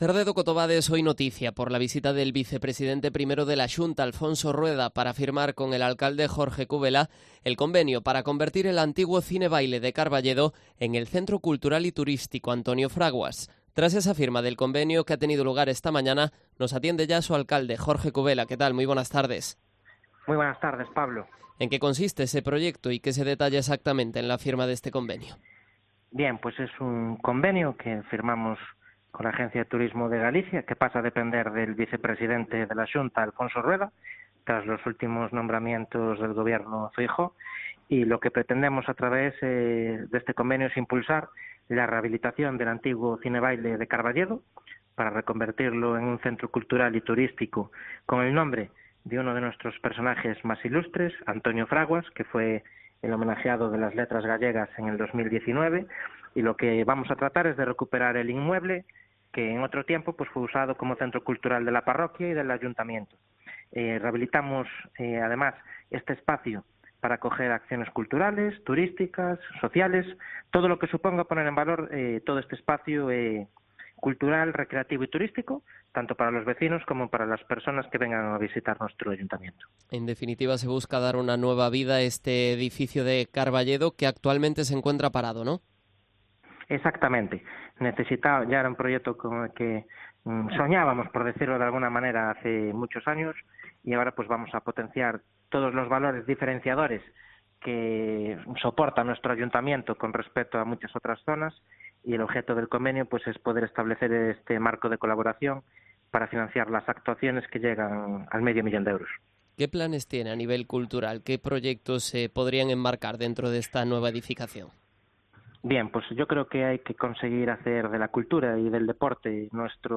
Entrevista al alcalde de Cerdedo-Cotobade, Jorge Cubela, en Cope Pontevedra